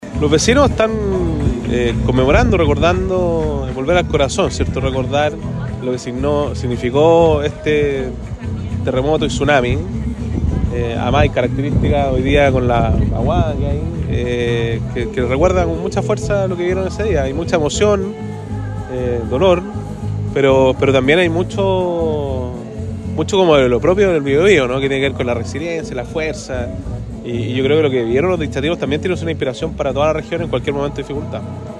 Durante la mañana de este jueves 27 de febrero, el gobernador del Biobío, Sergio Giacaman, se trasladó hasta la Plaza de la Ciudadanía de Dichato, para participar de una ceremonia de conmemoración del terremoto del 27 de febrero de 2010, organizada por el Comité de Vivienda de esa localidad.